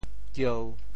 娇（嬌） 部首拼音 部首 女 总笔划 15 部外笔划 12 普通话 jiāo 潮州发音 潮州 gieu1 文 潮阳 giao1 文 澄海 giou1 文 揭阳 giao1 文 饶平 giao1 文 汕头 giao1 文 中文解释 潮州 gieu1 文 对应普通话: jiāo ①美好可爱：～儿 | ～女 | ～艾（年轻貌美的女子） | ～娆 | ～艳 | ～嗔 | ～逸（潇洒俊美）。